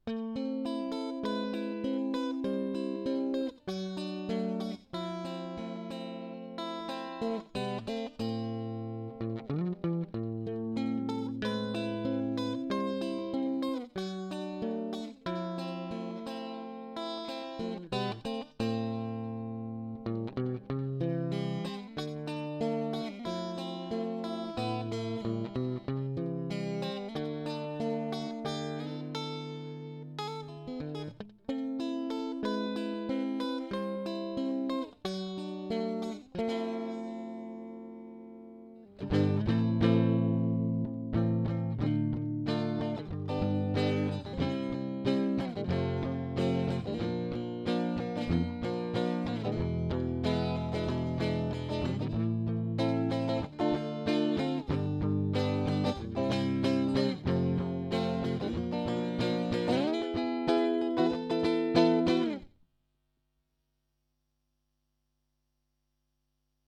Собрал на скорую руку (нечто вроде акустики) Фабфилтер Сатурн + импульс каба (гитарный не нашел).